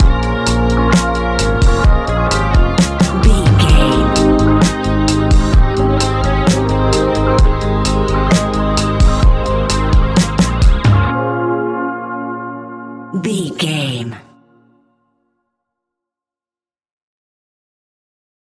Ionian/Major
A♯
chilled
laid back
Lounge
sparse
new age
chilled electronica
ambient
atmospheric